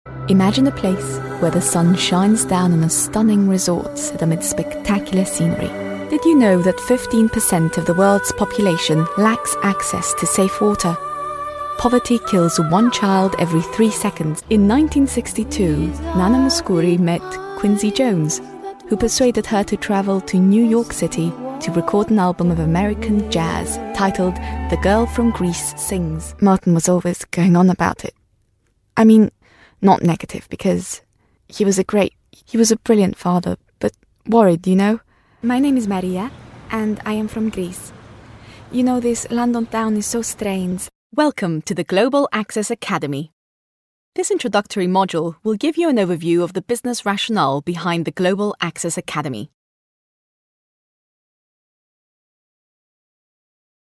English Montage: